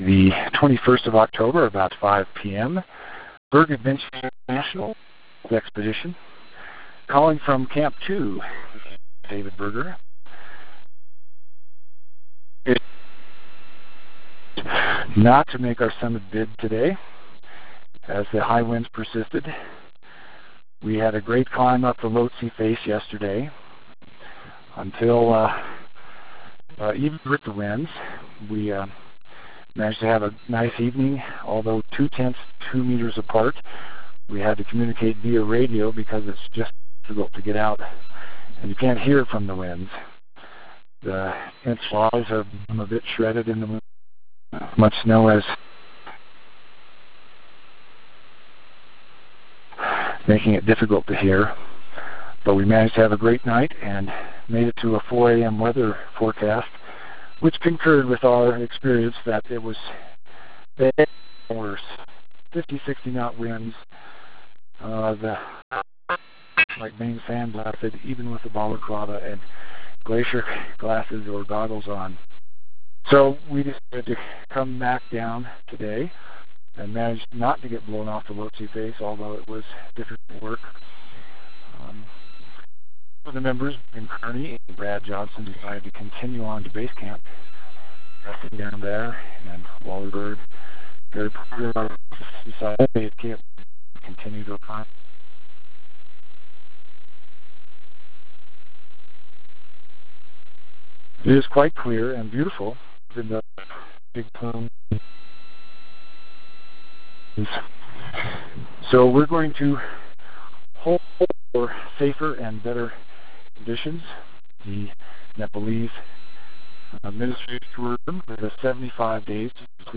(some inaudible sections)